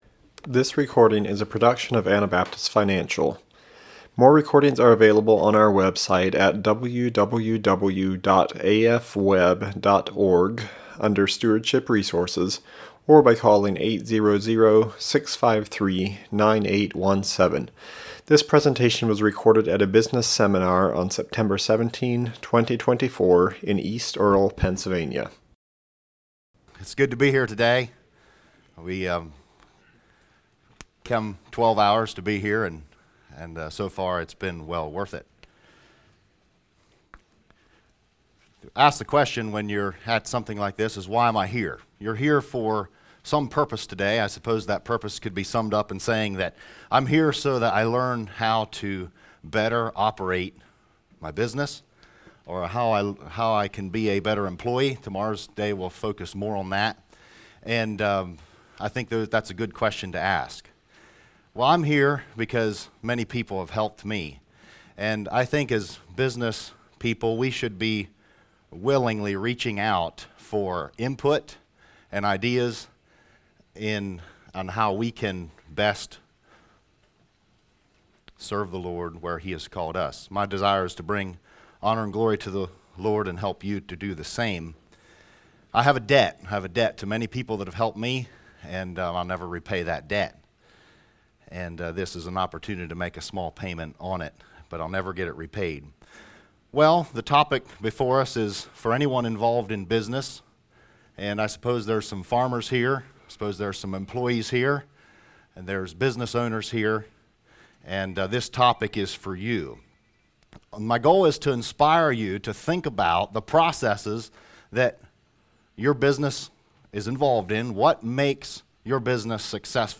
Pennsylvania Business Seminar 2024